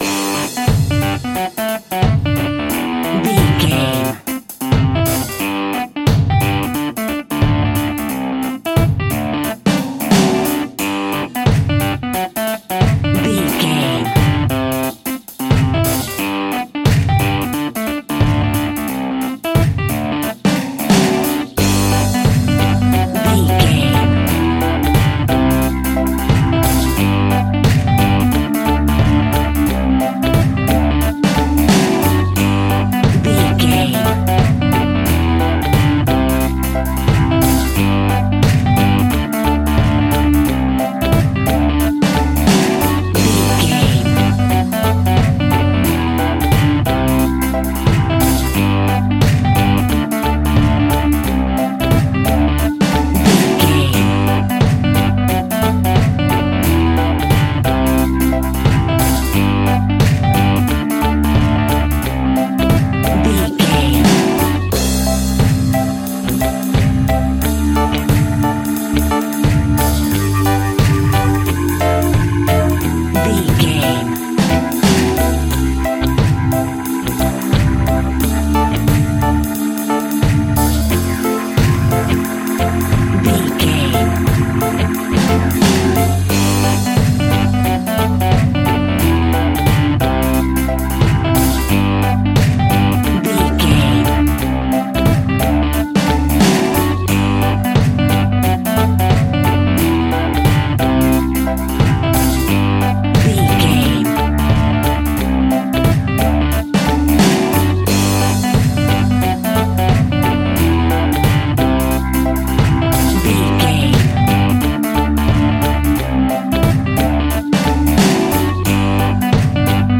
Hot summer reggae music from Barbados!
Ionian/Major
dub
reggae instrumentals
laid back
chilled
off beat
drums
skank guitar
hammond organ
percussion
horns